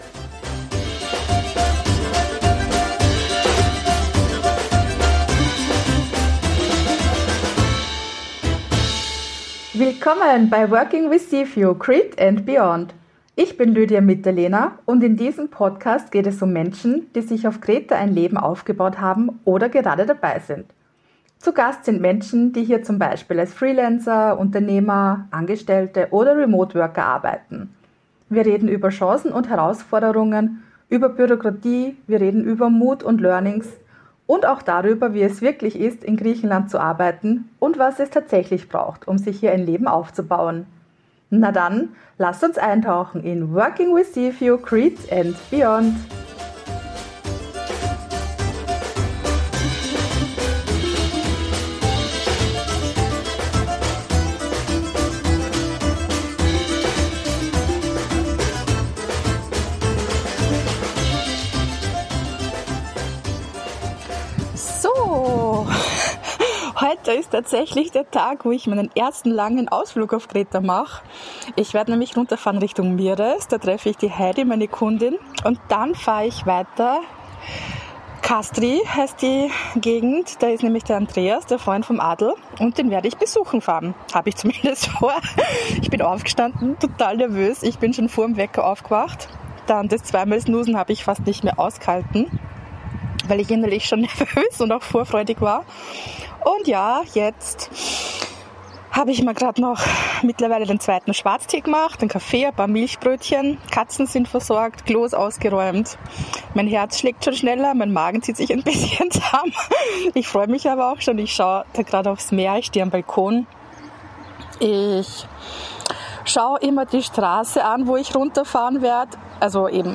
Ich habe während des Roadtrips Sprachnachrichten aufgenommen, die ich in dieser Folge mit dir teile. Es geht um Angst und darum, trotzdem weiterzufahren.